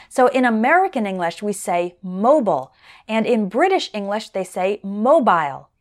In American English, we say “mo-bull,” and in British English, they say “mo-byle.”